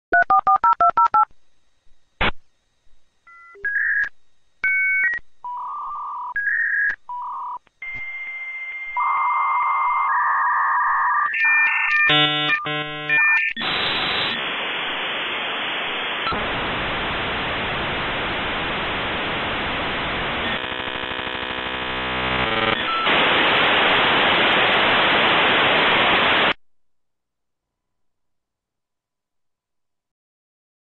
AOL-Sign-On-Dial-Up.m4a